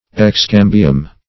Search Result for " excambium" : The Collaborative International Dictionary of English v.0.48: Excambion \Ex*cam"bi*on\, Excambium \Ex*cam"bi*um\, n. [LL. excambium.